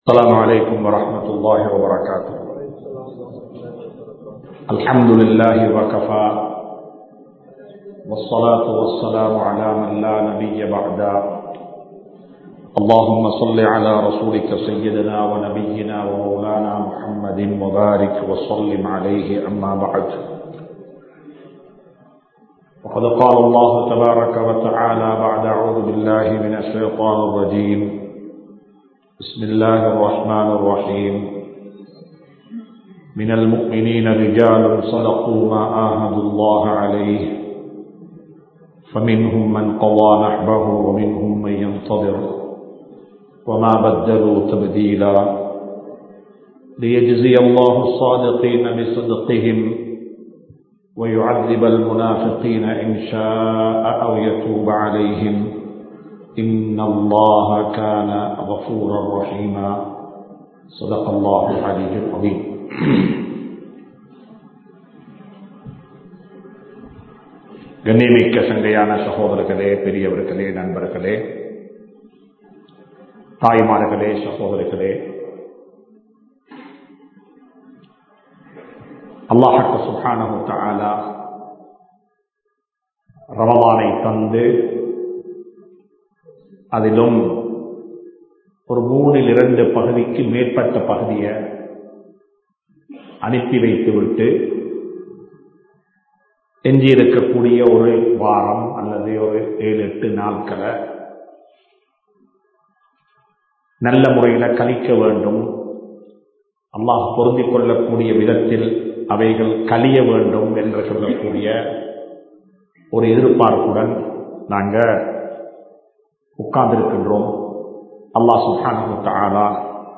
அல்குர்ஆன் கூறும் தௌபா | Audio Bayans | All Ceylon Muslim Youth Community | Addalaichenai
Kandy, Kattukela Jumua Masjith